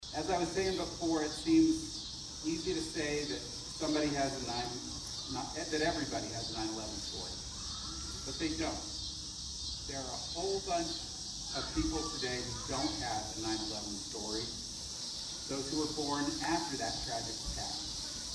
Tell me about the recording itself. City Park was host to the 9/11 Day of Remembrance on Sunday where several dignitaries were on hand for the event.